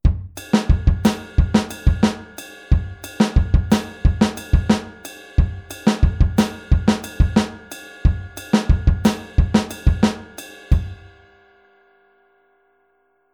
Rechte Hand wieder auf dem Kopfbecken